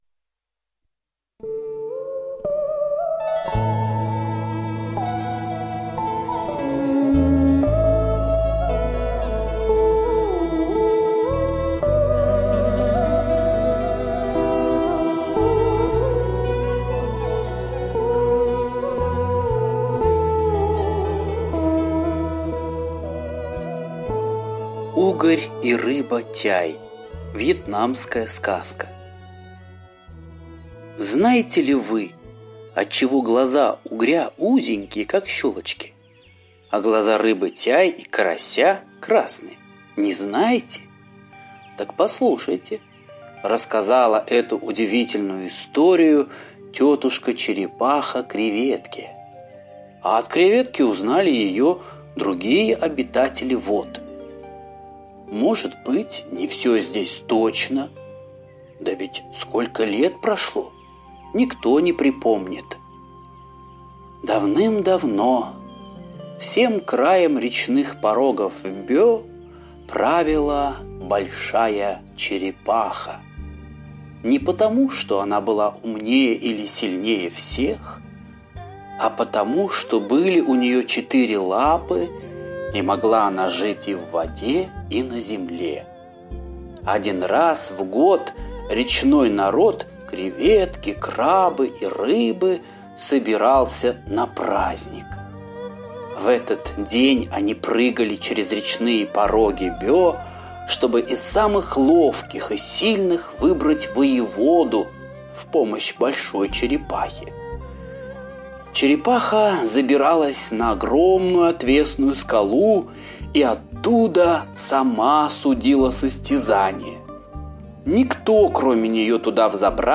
Угорь и рыба тяй - восточная аудиосказка - слушать онлайн